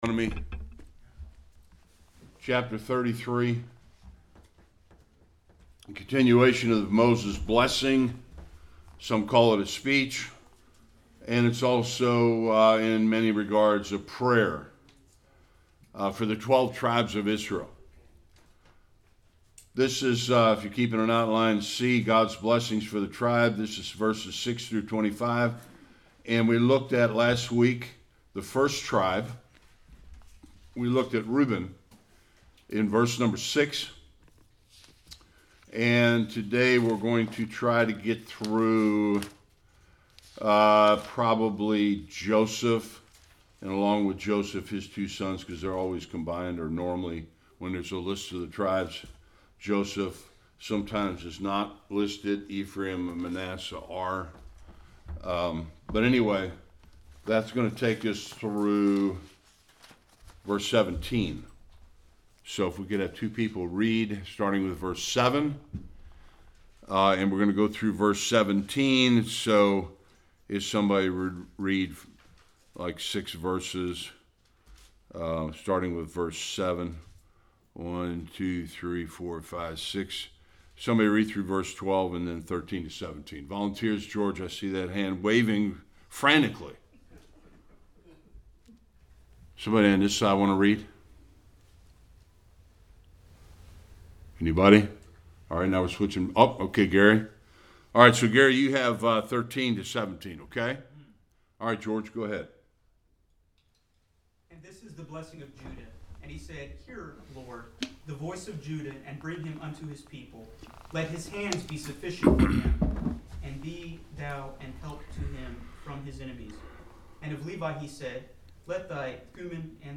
7-17 Service Type: Sunday School Moses’s prayer for the tribes of Judah